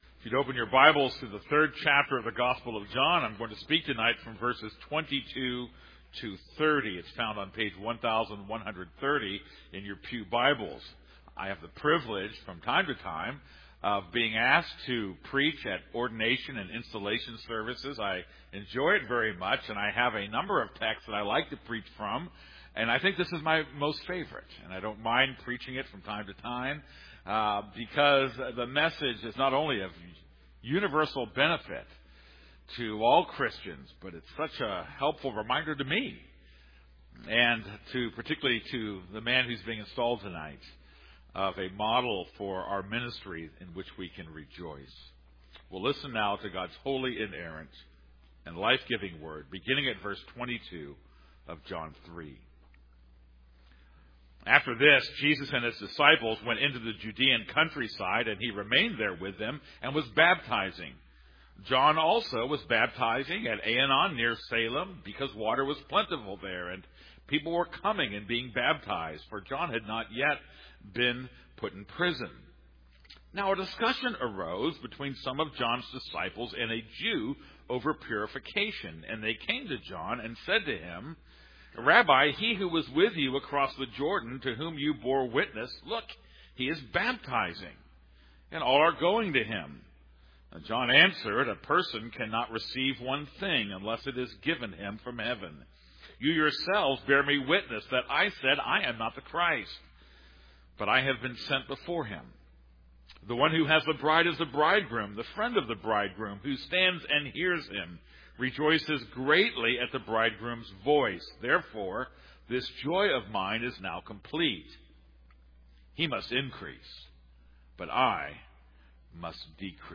This is a sermon on John 3:22-30.